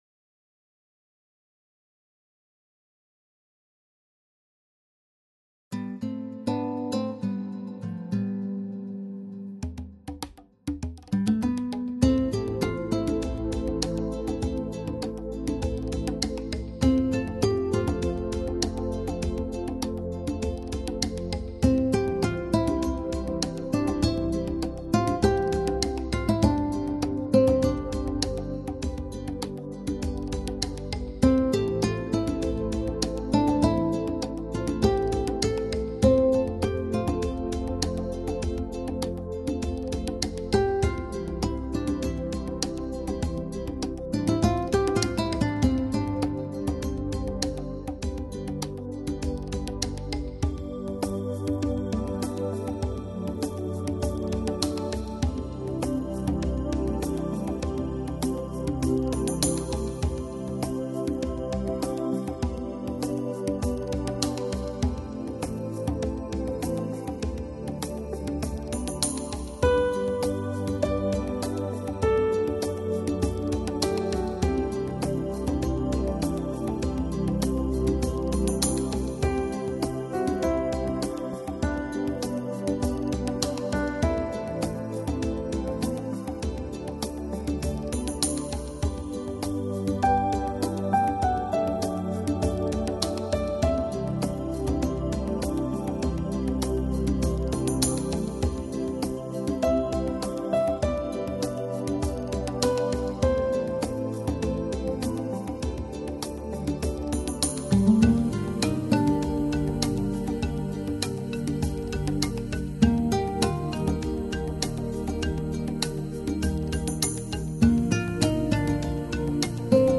Жанр: New Age, Relax